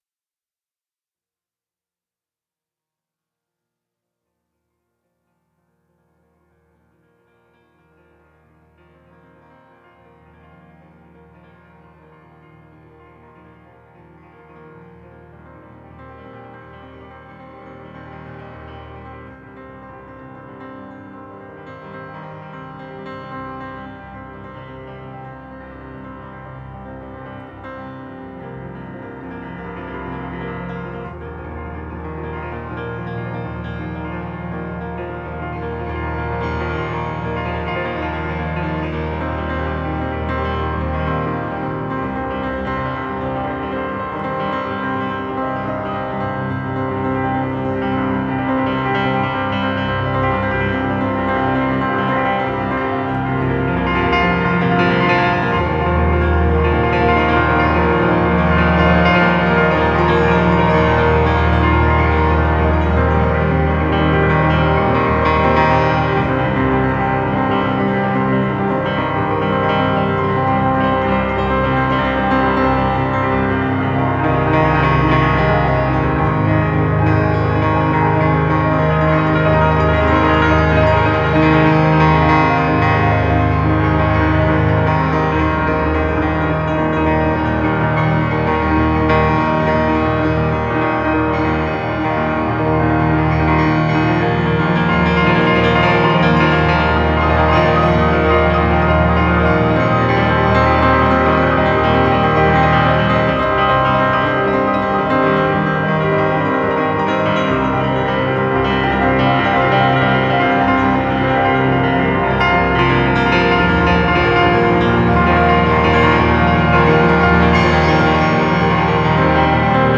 piano plus
Bass
Gitarre
Perkussion